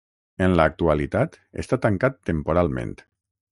Pronounced as (IPA) [təŋˈkat]